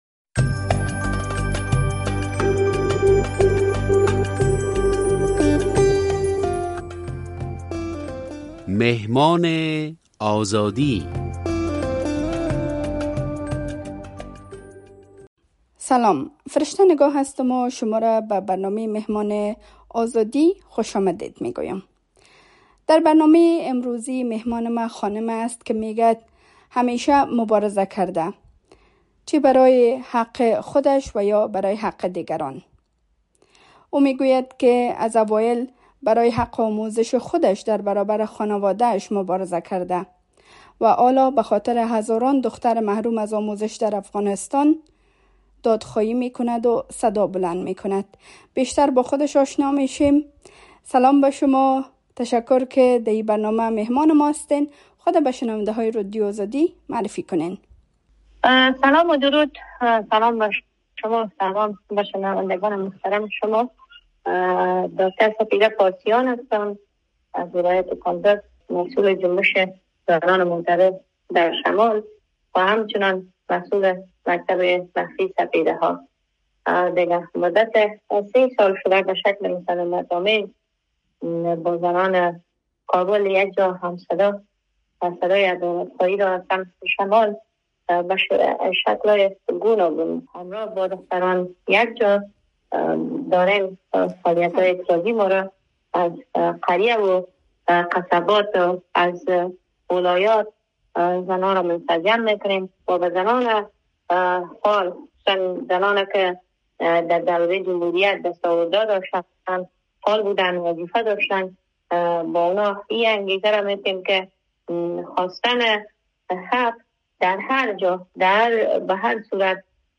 "مهمان آزادی": گفت‌وگو با زنی که برای حق آموزش در افغانستان مبارزه می‌کند